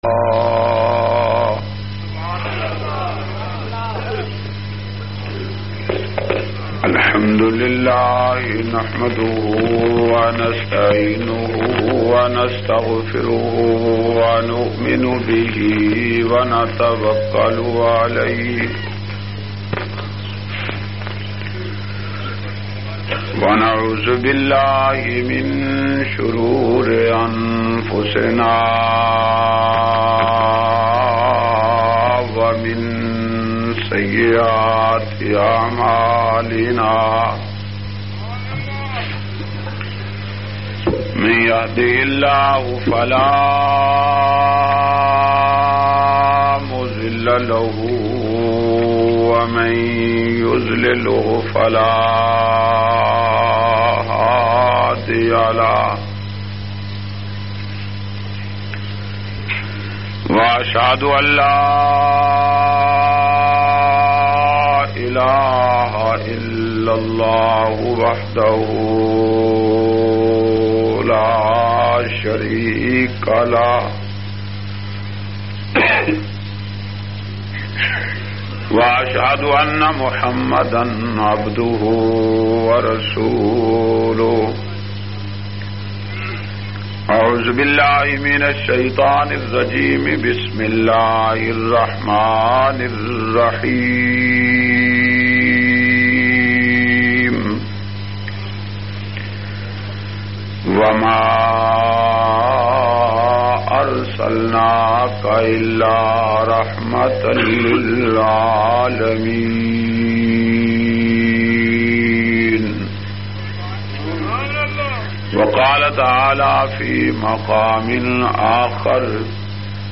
320- Nabi Ka Roza Jannat Punjabi Bayan.mp3